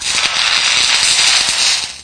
Welder2.ogg